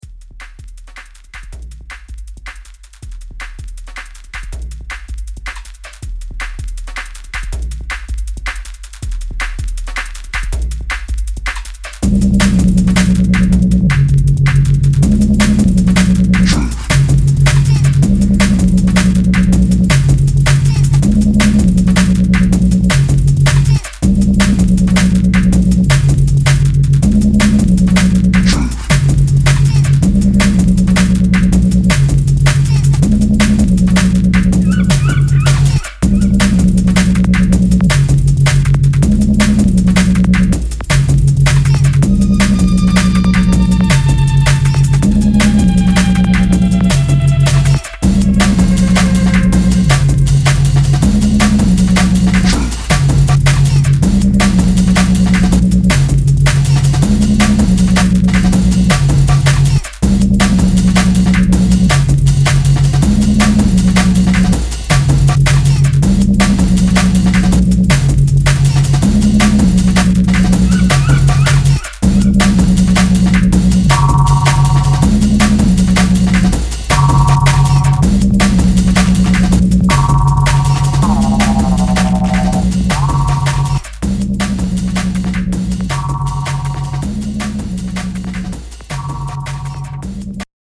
An exploration in Bass, Uk Rave and Jungle